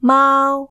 [maːu̯˥] 'cat' See Cantonese phonology